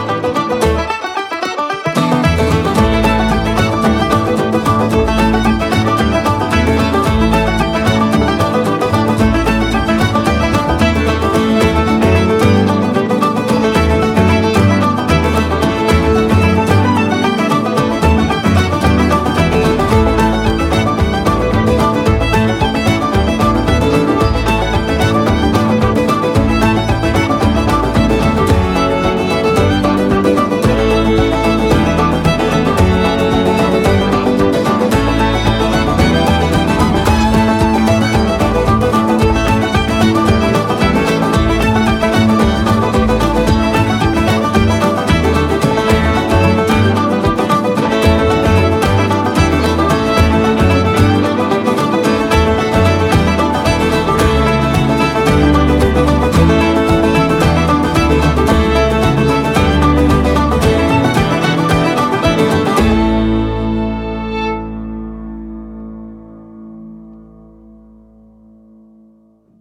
Double Bass
recorded in Bann View studios, Co. Antrim
The Peacocks and the stirring all guns blazing closer